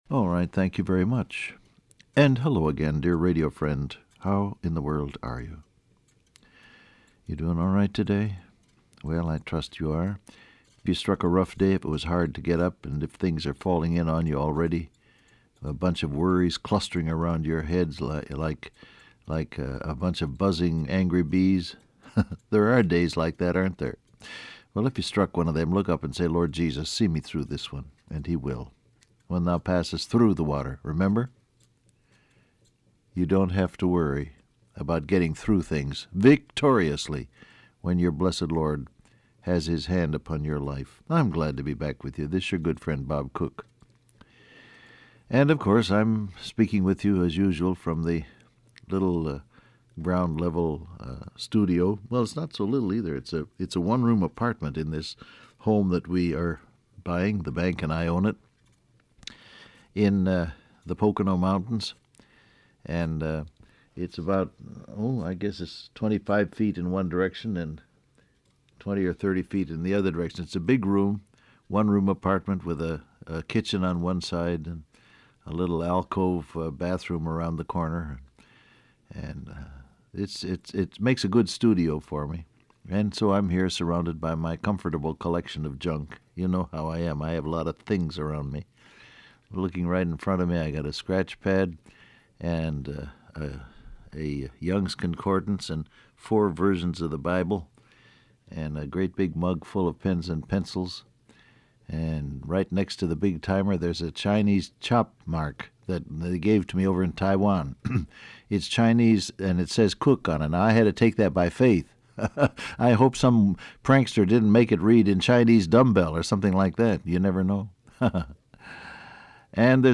Download Audio Print Broadcast #7169 Scripture: 1 Peter 4:12-15 , Ephesians 4:28 Topics: Joy , Trials , Glory , Pressure , Suffering , Blessed Transcript Facebook Twitter WhatsApp Alright, thank you very much.